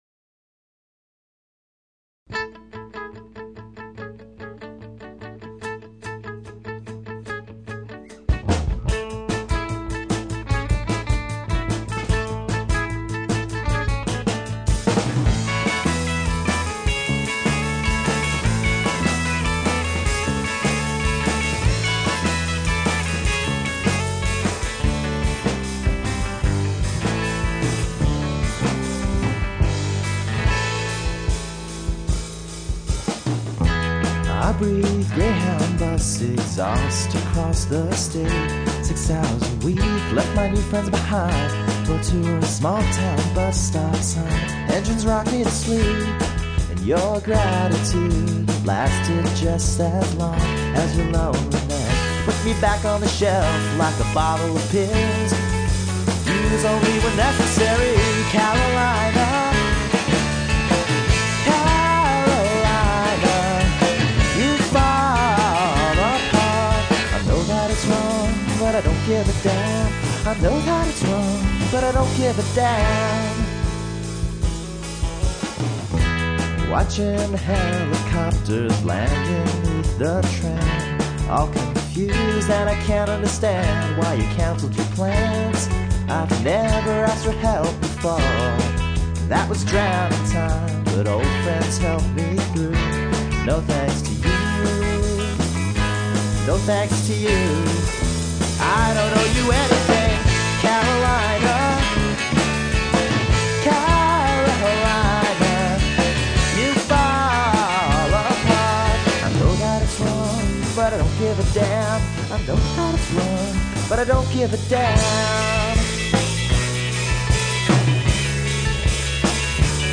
The set started off with the demo opener,